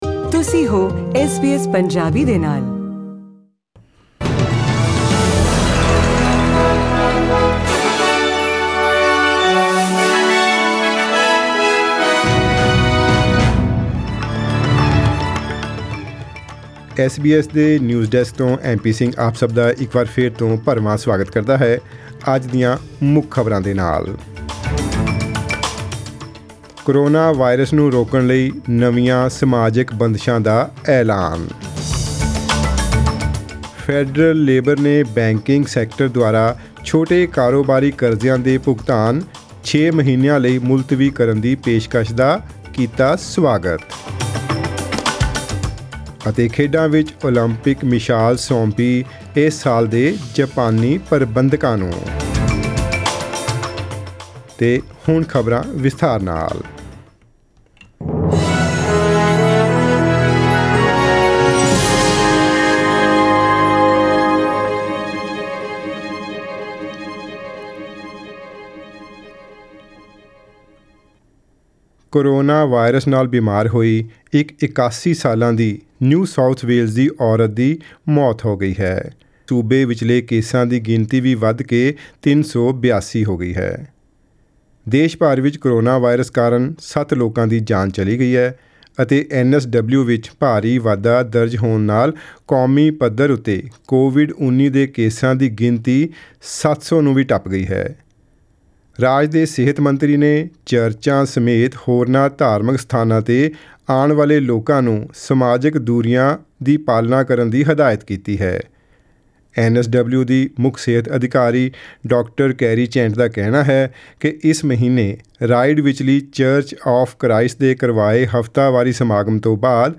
In today’s news bulletin: New indoor social distancing rules for events of less than 100 people to stop spread of coronavirus, Federal Labor supports the banking sector's offer of six-month payment deferrals for small business loans, And in sport, the Olympic flame is passed onto organisers of this year's Tokyo Games in Japan